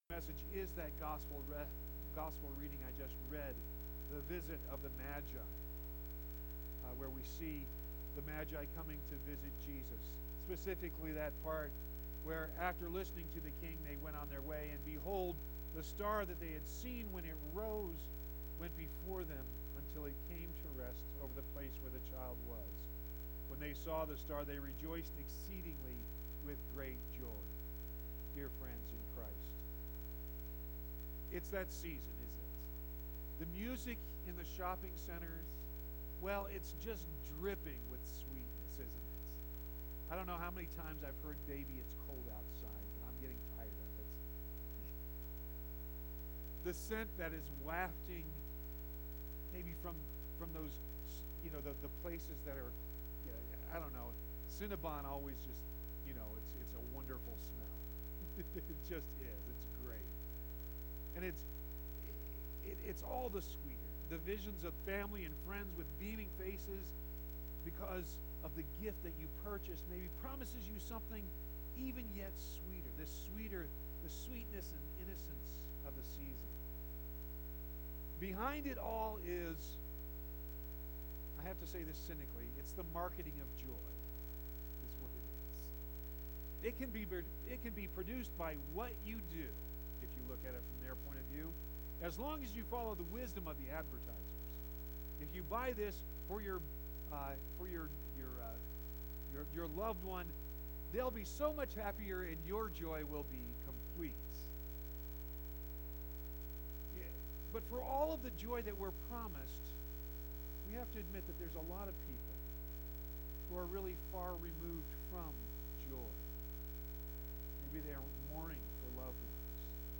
Advent Midweek 12.11.19
You can also download the sermon directly HERE, or get all the sermons on your phone by subscribing to our Podcast HERE.